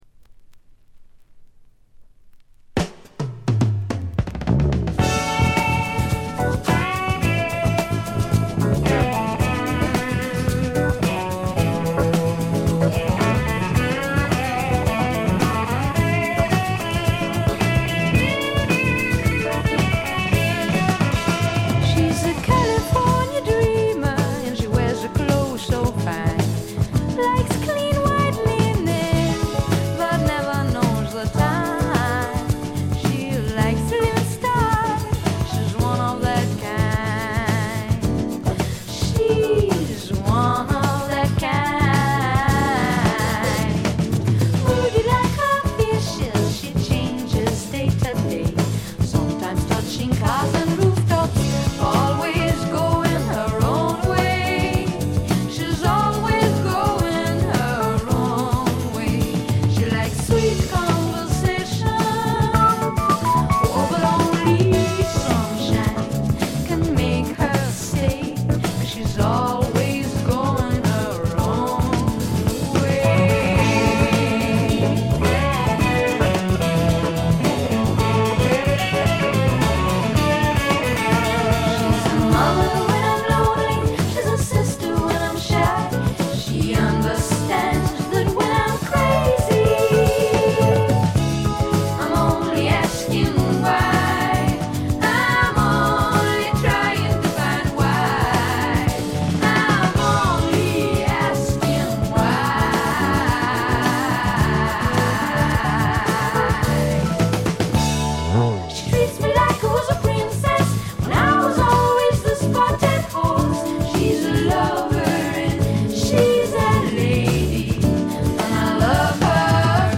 これ以外はところどころでチリプチ。
サウンドは時代なりにポップになりましたが可愛らしい歌声は相変わらずなのでおじさんはご安心ください。
クラブ人気も高い女性ポップの快作です。
試聴曲は現品からの取り込み音源です。